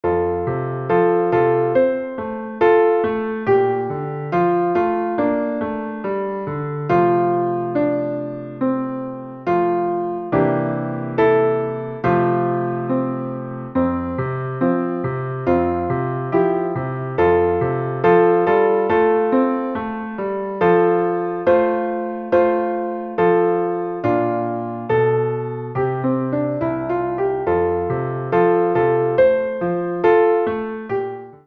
ピアノ伴奏 タグ